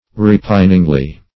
repiningly - definition of repiningly - synonyms, pronunciation, spelling from Free Dictionary Search Result for " repiningly" : The Collaborative International Dictionary of English v.0.48: Repiningly \Re*pin"ing*ly\, adv.